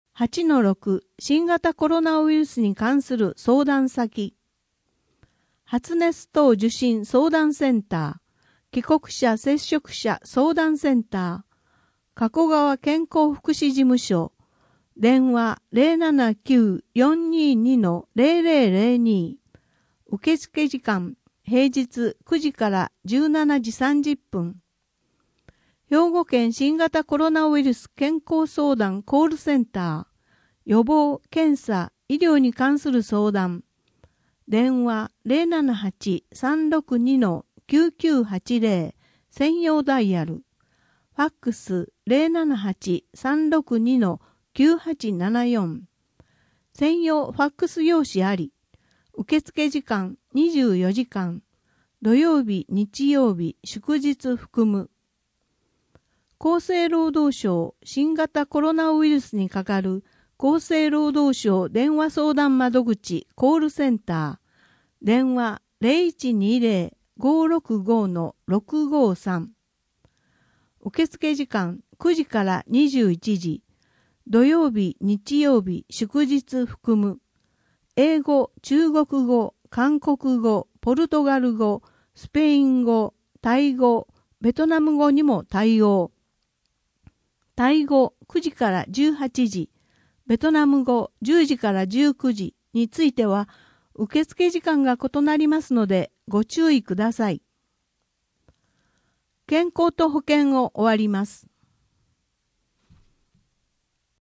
声の「広報はりま」4月号
声の「広報はりま」はボランティアグループ「のぎく」のご協力により作成されています。